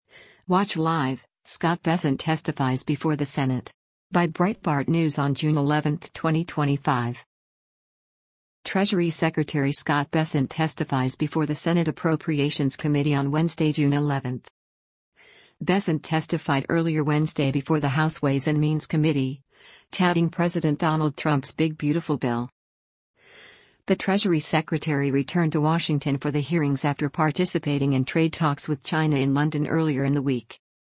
Treasury Secretary Scott Bessent testifies before the Senate Appropriations Committee on Wednesday, June 11.